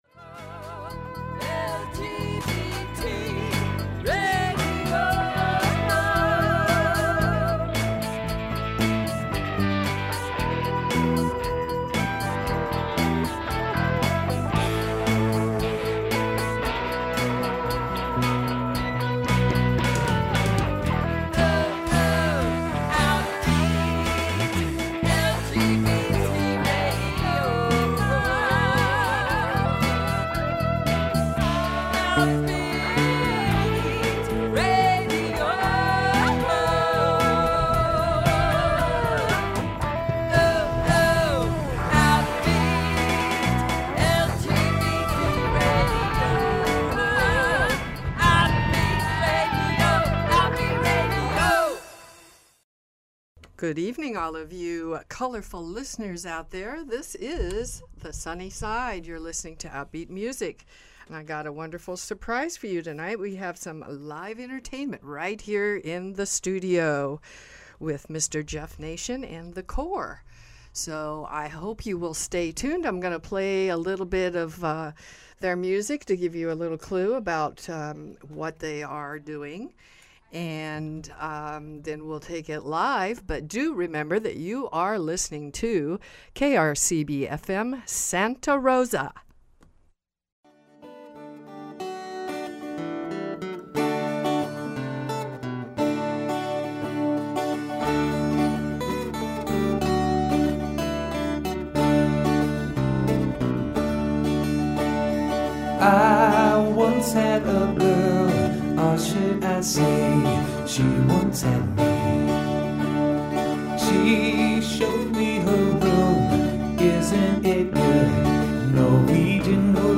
singer, songwriter, musicians. In studio with Host